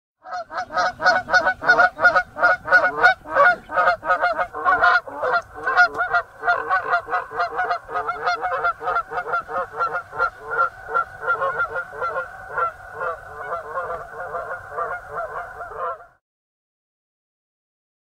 دانلود آهنگ غاز 2 از افکت صوتی انسان و موجودات زنده
دانلود صدای غاز 2 از ساعد نیوز با لینک مستقیم و کیفیت بالا
جلوه های صوتی